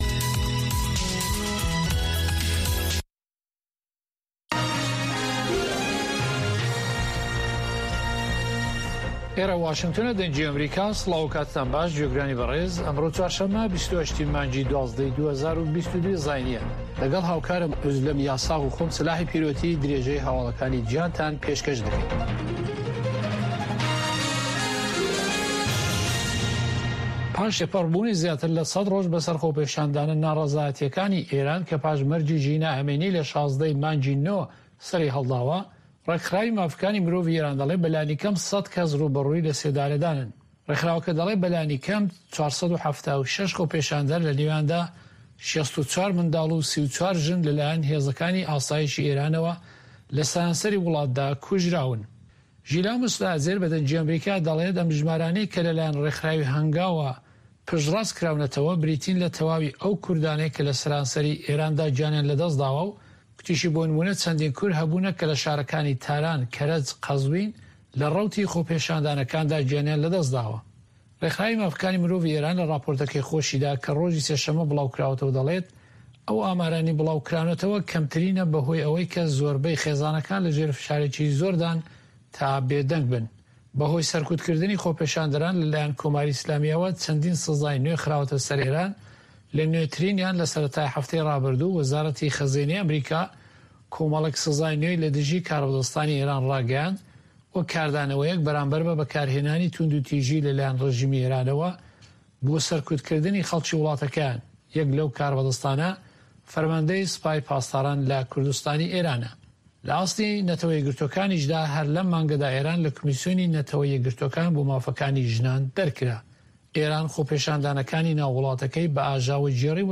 هەواڵە جیهانییەکان 1